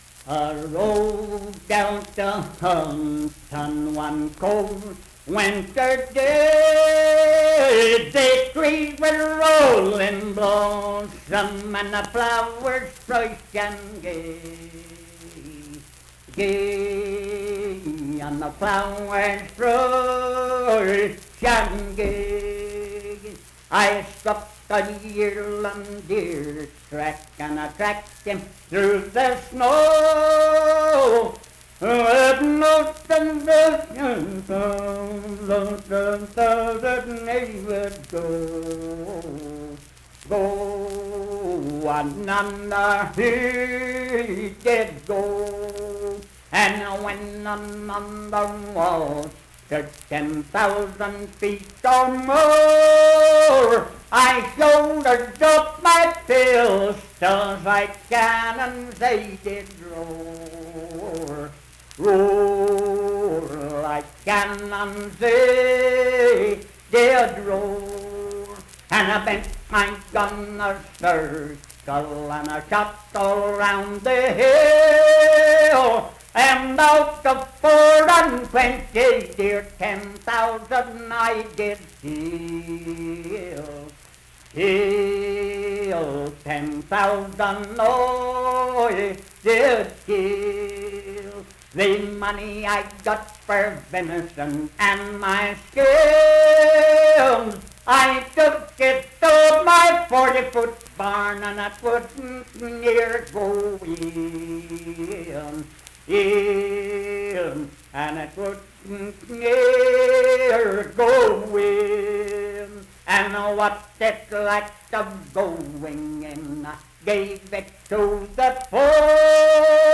I Rode Out A-Hunting - West Virginia Folk Music | WVU Libraries
Unaccompanied vocal music performance
Verse-refrain 7(5w/R).
Voice (sung)